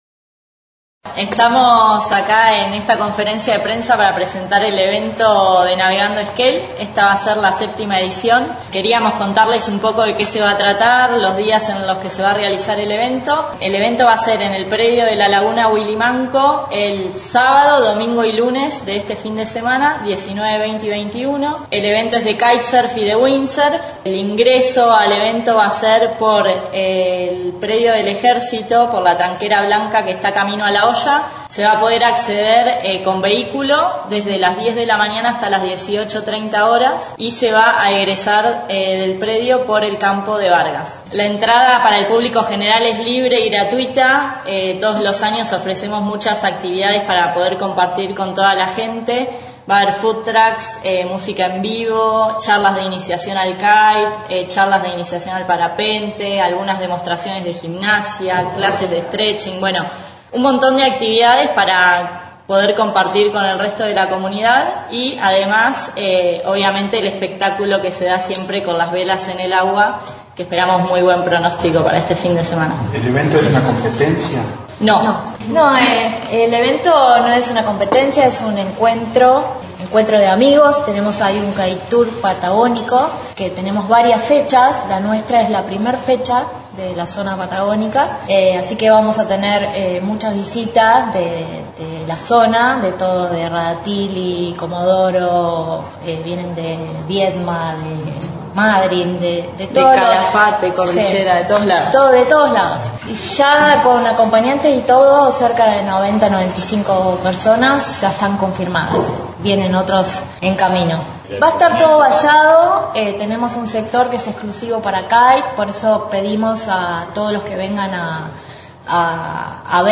En conferencia de prensa, integrantes de la Asociación Civil Navegando Esquel, presentaron a 7° edición del evento de Kite Surf y Wind Surf que se desarrollará este fin de semana en el predio de la laguna Willmanco.